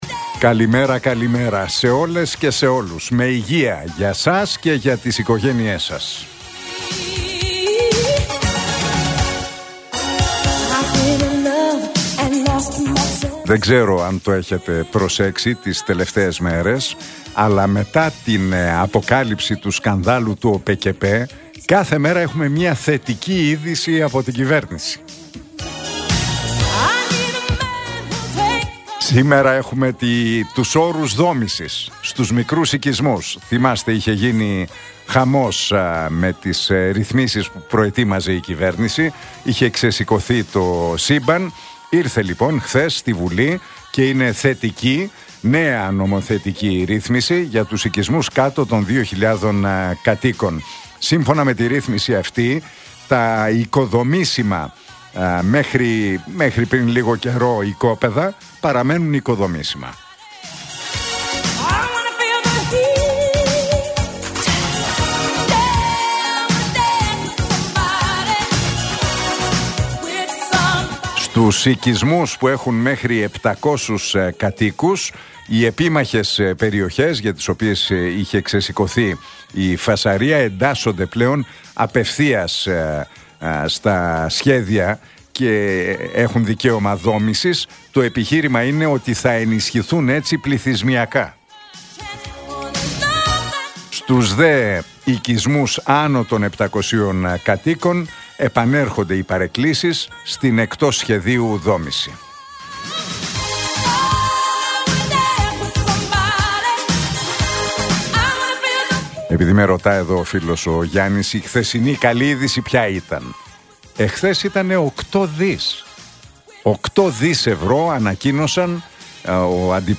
Ακούστε το σχόλιο του Νίκου Χατζηνικολάου στον ραδιοφωνικό σταθμό Realfm 97,8, την Τετάρτη 2 Ιουλίου 2025.